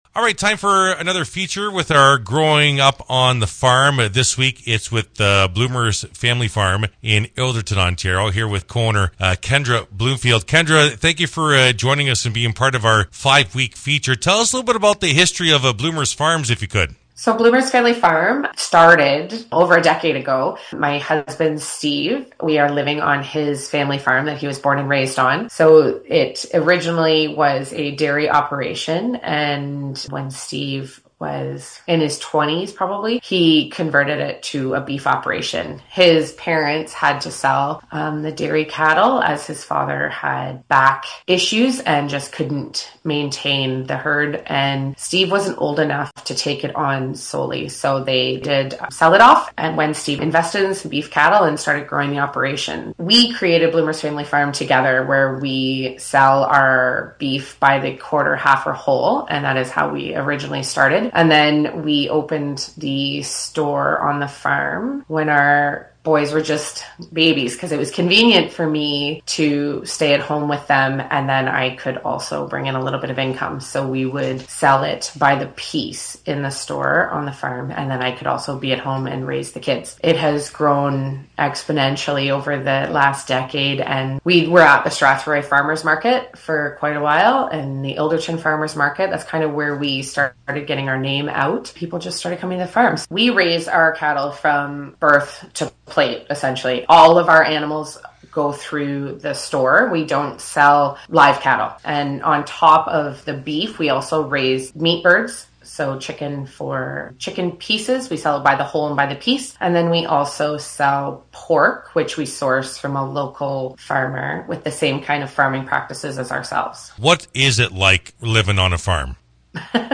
Here is the full interview